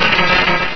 Cri de Solaroc dans Pokémon Rubis et Saphir.